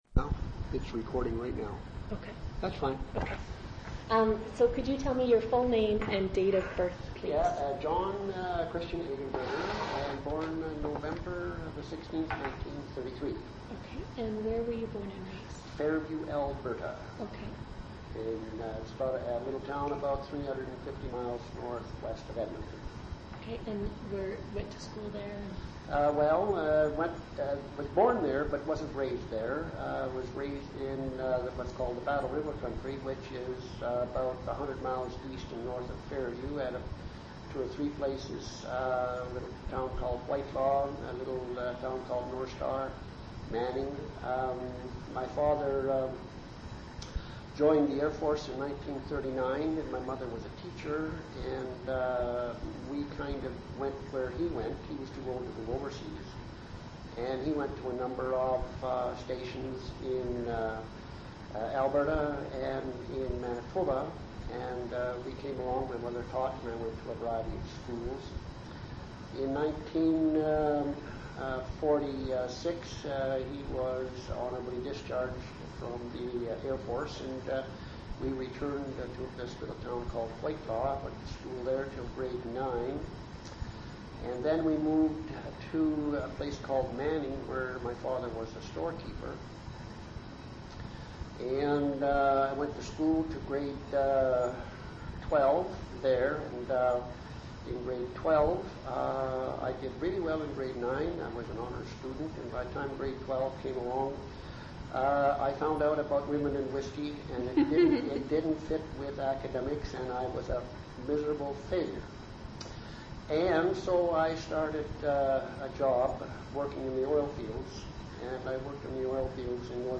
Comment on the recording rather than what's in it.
Resource type Sound Rights statement In Copyright - Educational Use Permitted Extent 2 sound recordings (MP3) Geographic Coverage France Coordinates 46, 2 Additional physical characteristics Original sound recording on audio cassette also available.